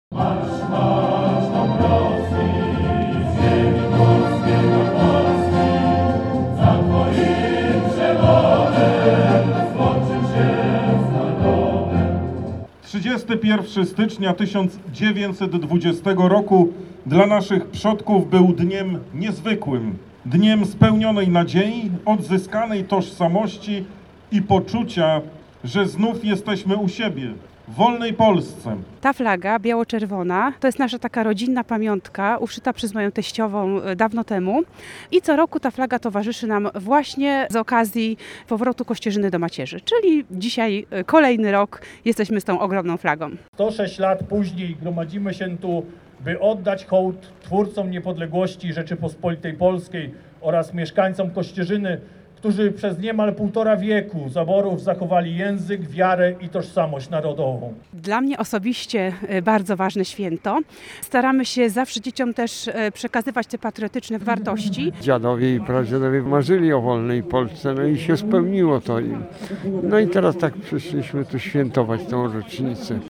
Na miejscu uroczystości był nasz reporter.